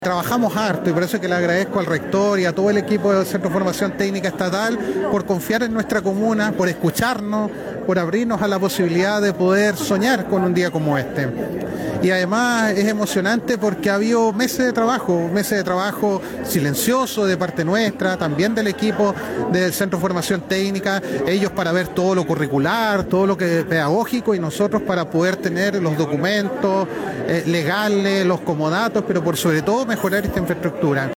El edil, manifestó su emoción ante este gran momento para los jóvenes que